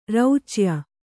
♪ rauchya